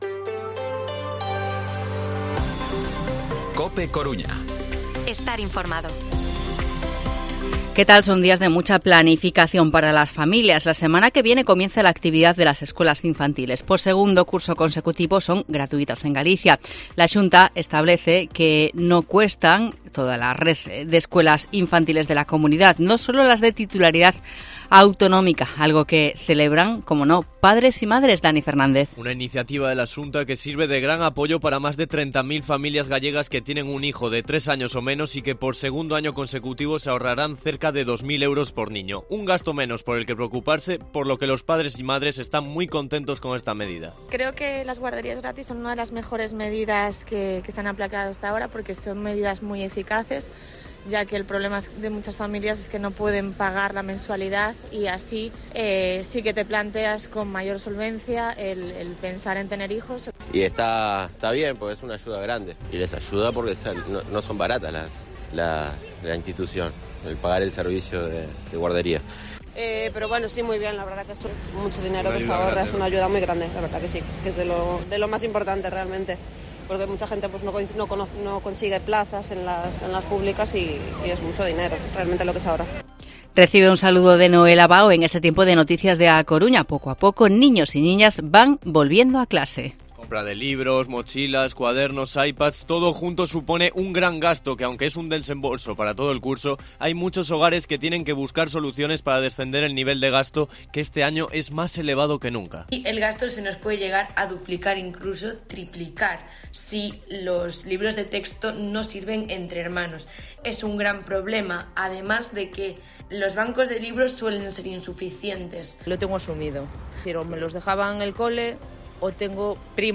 Informativo Mediodía en COPE Coruña jueves, 31 de agosto de 2023 14:20-14:30